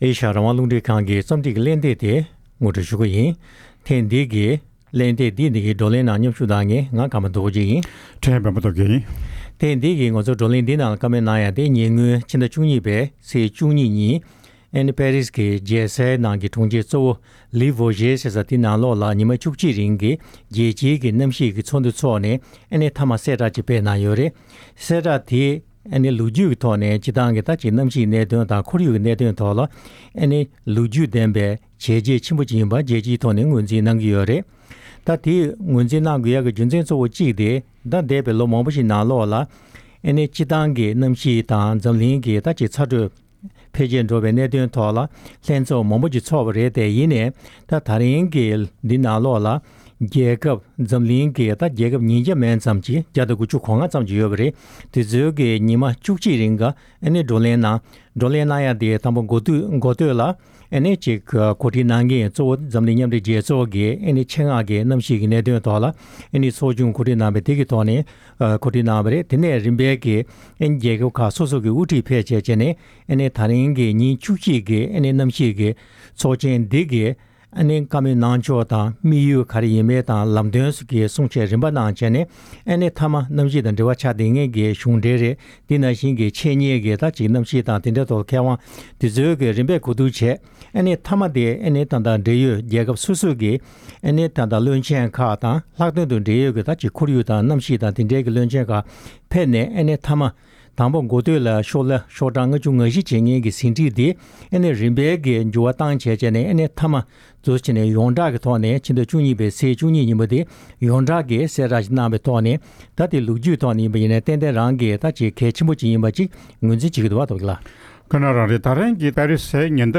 དཔྱད་གླེང་གནང་བར་གསན་རོགས